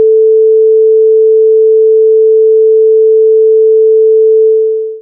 Ascolta questo suono: è la nota LA, prodotta da una frequenza di 440 Hz.
frequenza_test_01.mp3